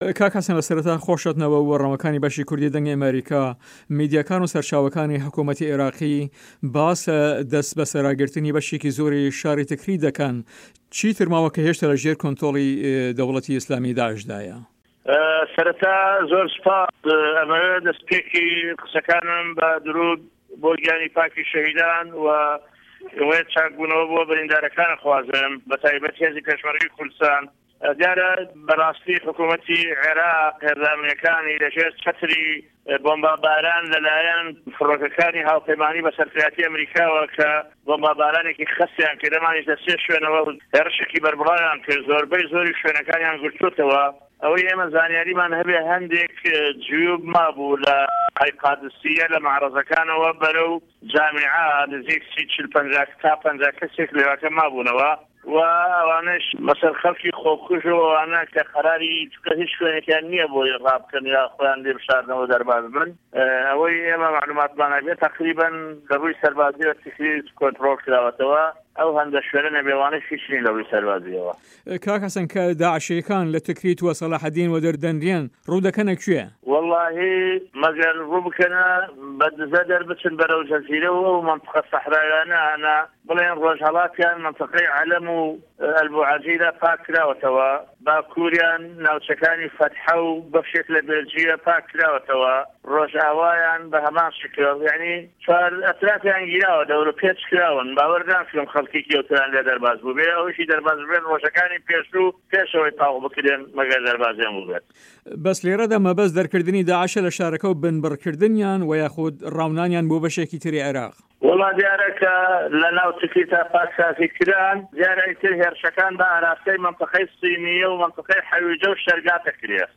حه‌سه‌ن مه‌حه‌مه‌د ئه‌ندامی ئه‌نجومه‌نی پاریزگای سه‌لاحه‌دین له‌ هه‌ڤپه‌یڤینێکدا له‌گه‌ڵ به‌شی کوردی ده‌نگی ئه‌مه‌ریکا ده‌ڵێت" مه‌گه‌ر روو بکه‌نه‌ جه‌زیره‌ و ئه‌و ناوچه‌ ده‌شتاویانه‌، رۆژهه‌ڵات و ناوچه‌ی عه‌له‌م، چوار ده‌وریان گیراوه‌ باوه‌ر ناکه‌م خه‌ڵکێکی ئه‌وتۆیان لێ ده‌رباز بۆبێ.